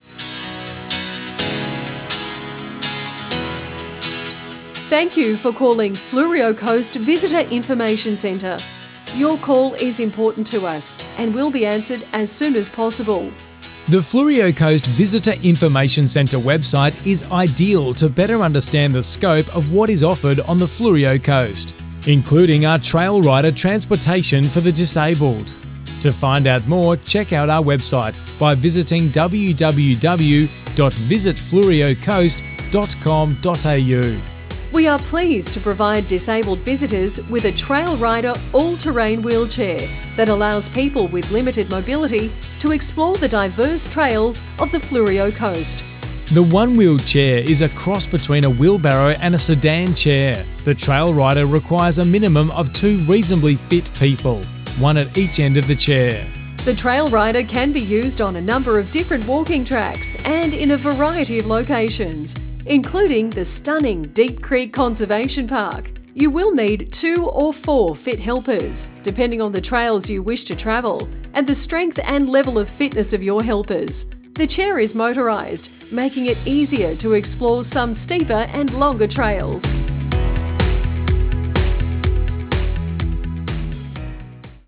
All messages include royalty free music.
Messages on Hold